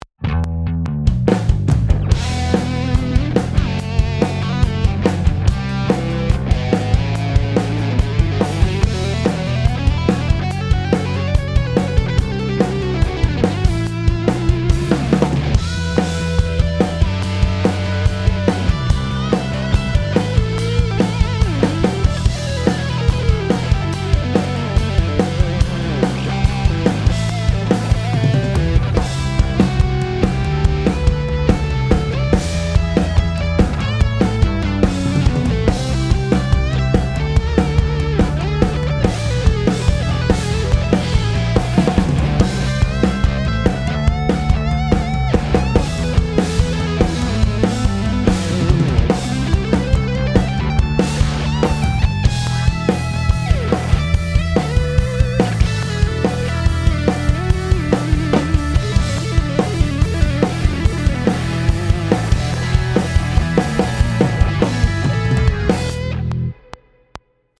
Punk Prog Drums Recording Rock